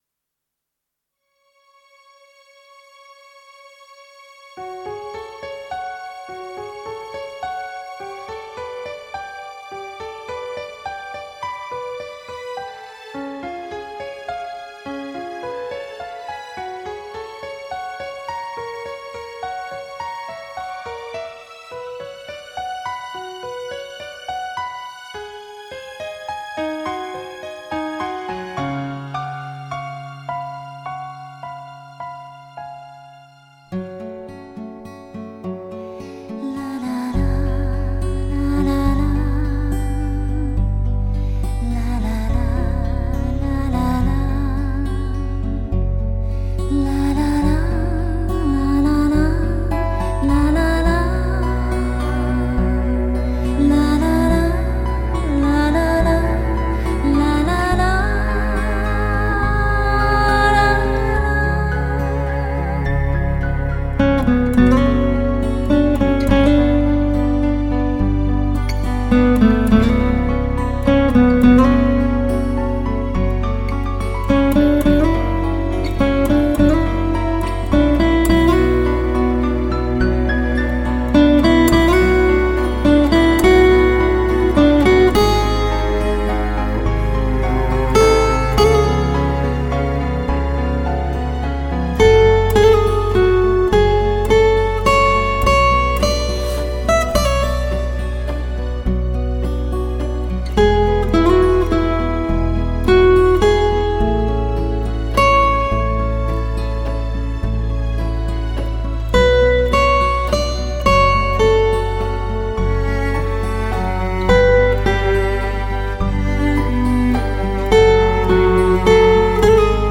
经过效果器的处理
HiFi吉他典范
跳荡的音符 曼妙的旋律 另一纯净的境界
碟中主音吉他，有着浓郁的感情色彩，是佛拉明哥与爵士乐完美的组合，空气感和速度上佳，中音圆润、高频则更为明亮通透。